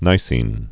(nīsēn, nī-sēn)